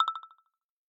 session-ios / Session / Meta / AudioFiles / messageReceivedSounds / bamboo-quiet.aifc
bamboo-quiet.aifc